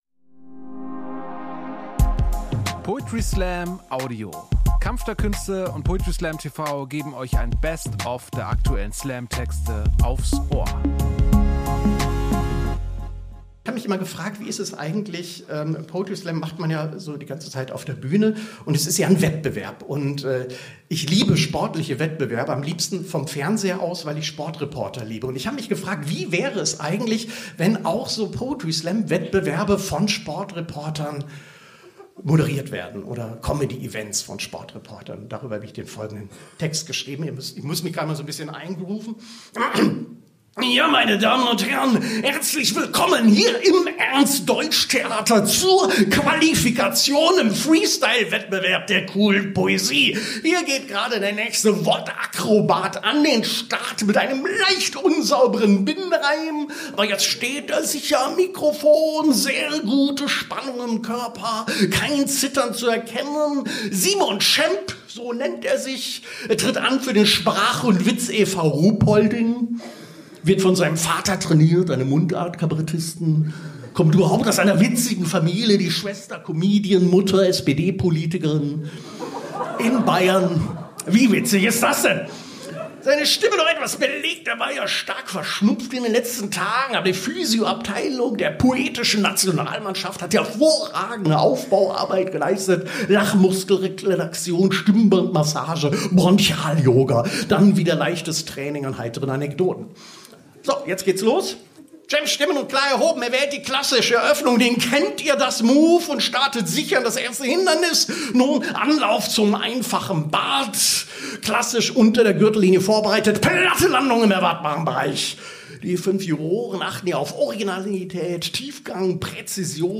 Stage: Ernst Deutsch Theater, Hamburg